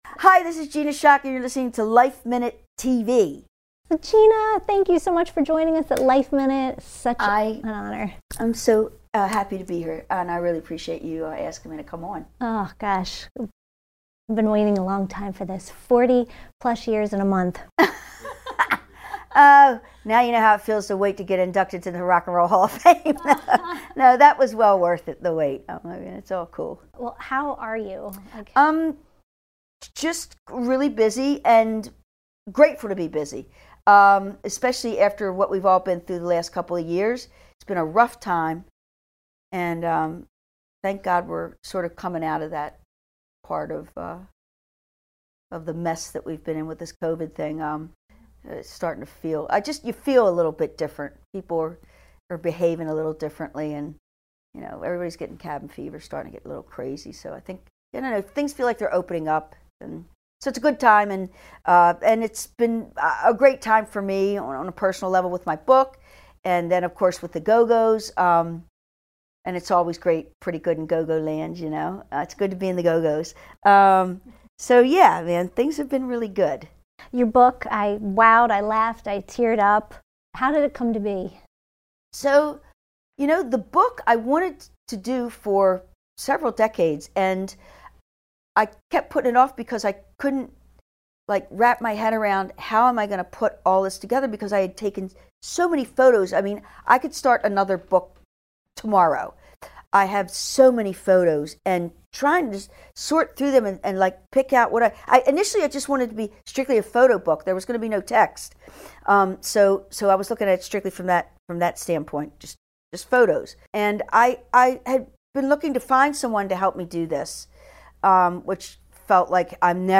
The beautiful, innately cool, hard-to-believe 64-year-old Schock graced the LifeMinute studios with her presence recently to tell us all about it and more.